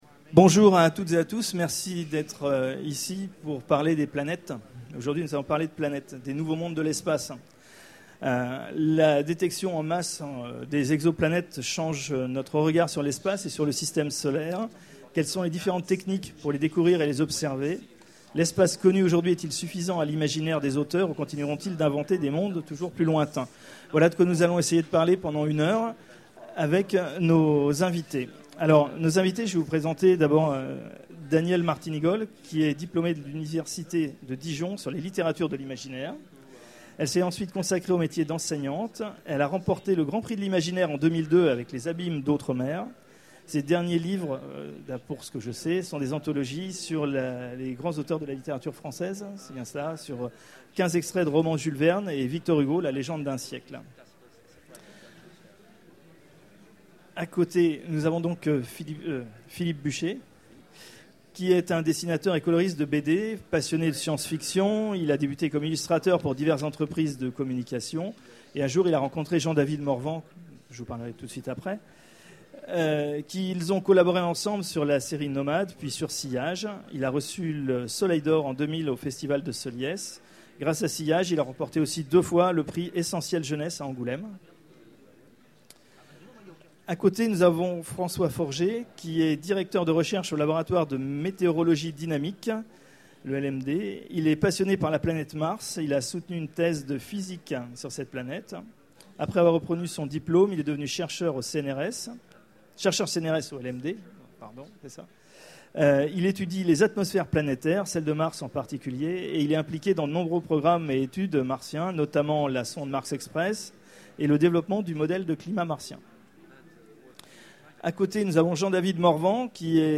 Utopiales 12 : Conférence Qu’est-ce qu’une planète habitable ?